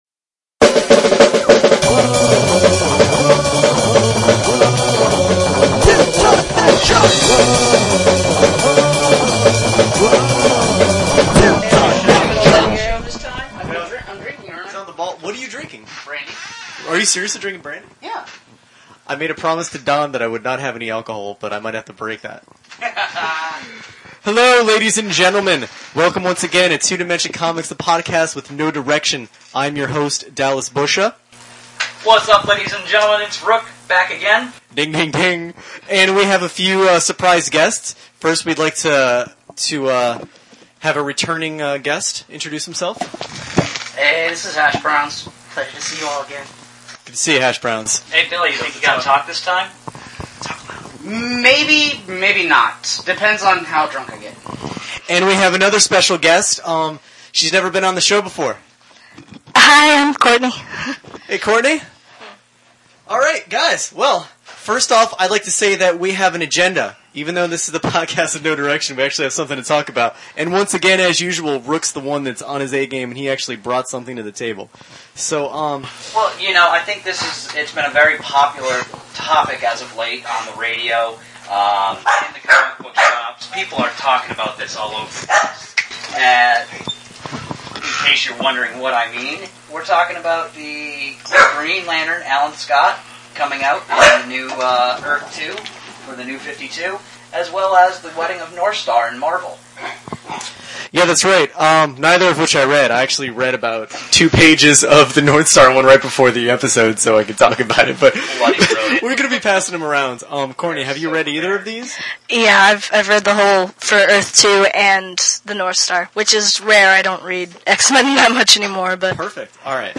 Warning: Technical Issues continue. We apologize for the terrible sound quality.